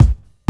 Kick (1).WAV